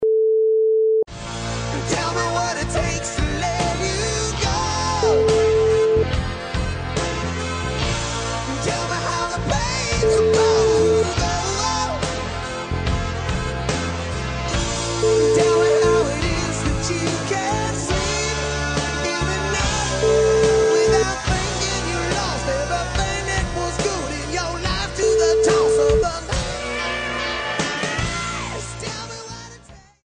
Live At Yokhama Arena, Japan